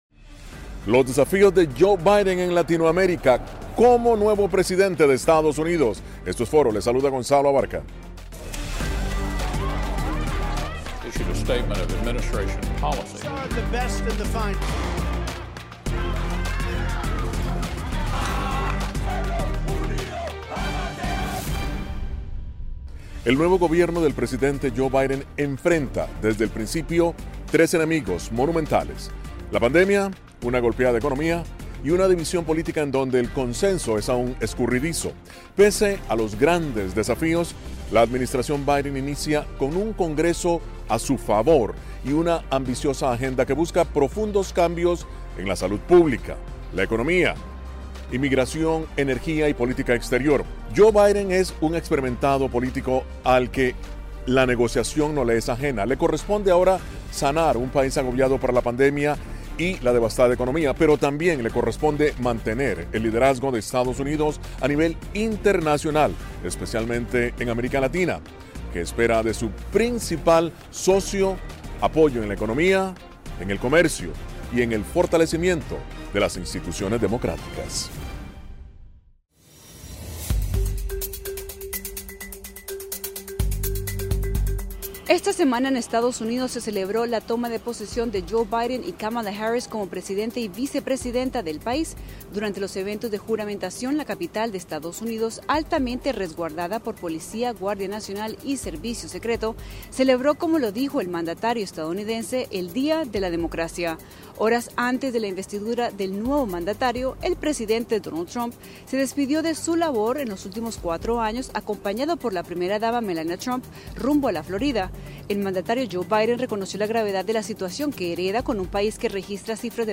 Foro: Nueva era Biden y América Latina